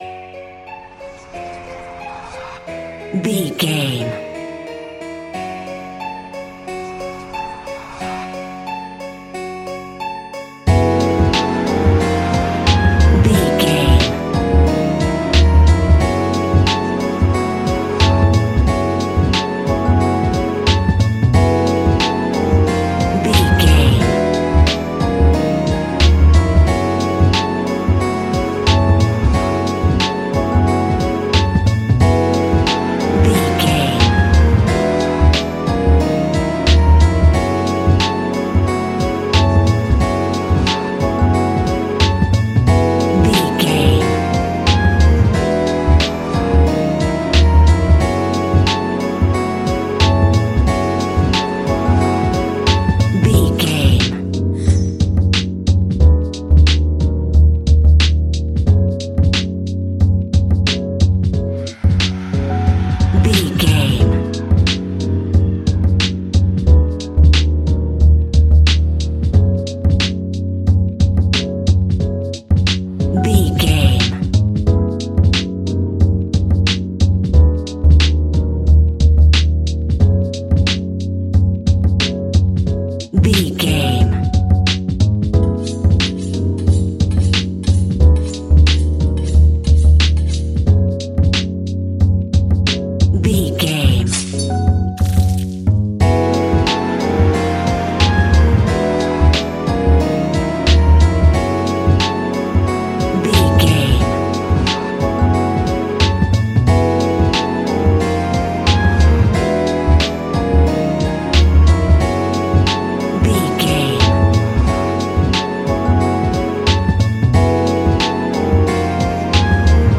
Ionian/Major
F♯
laid back
Lounge
sparse
new age
chilled electronica
ambient
atmospheric